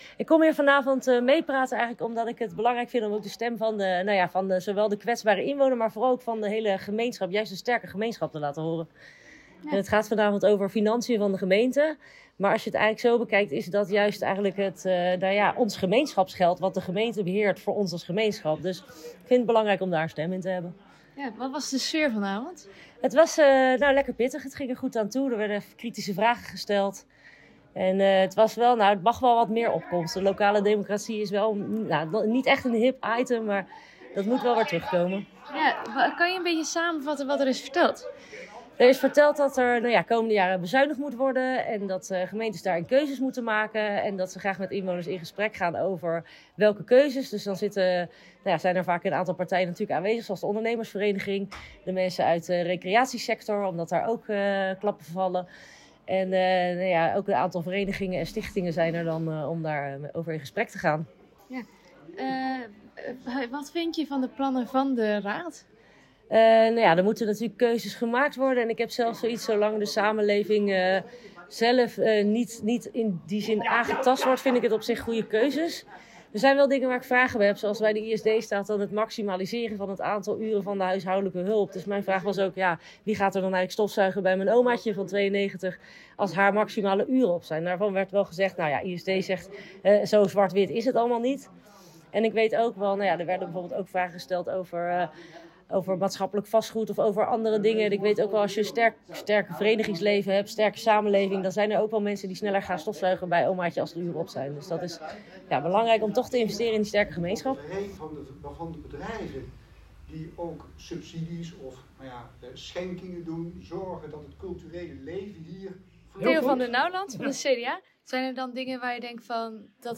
Radioreportage